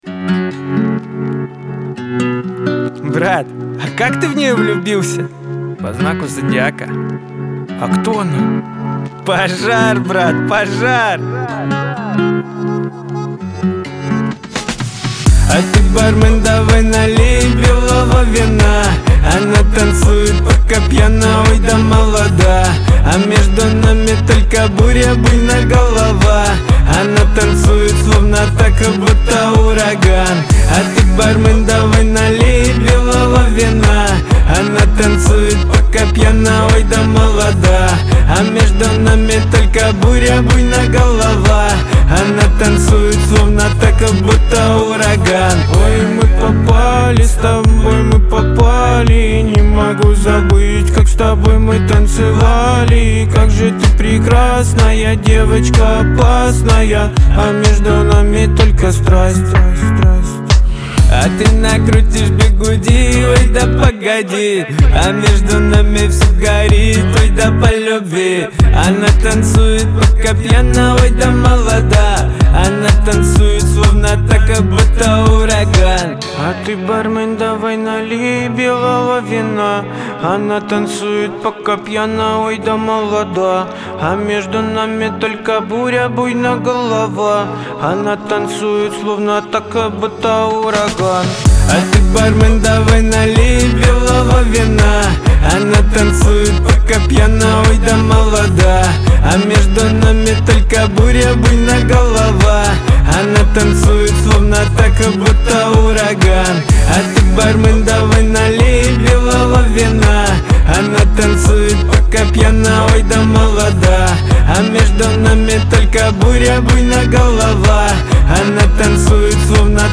Веселая музыка , pop
dance